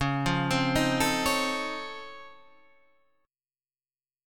C#+M9 chord